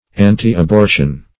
\an`ti-ab*or"tion\